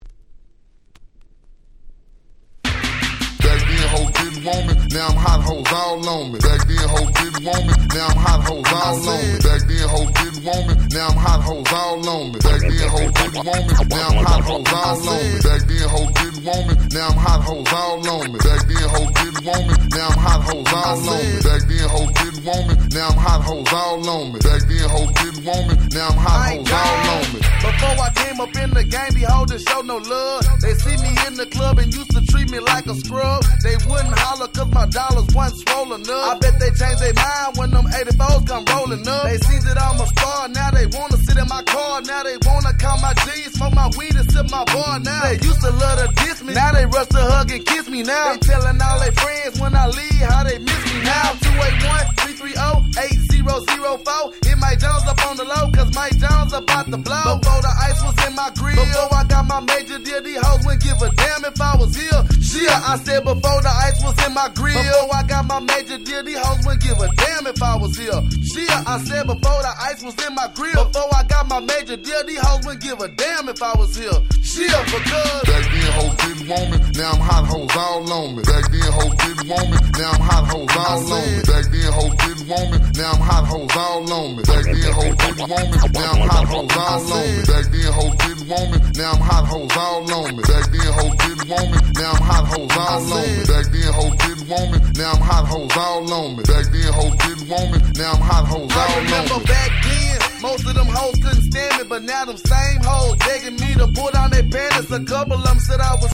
05' Super Hit Hip Hop !!
ブリブリのSouthバウンスチューンで最高！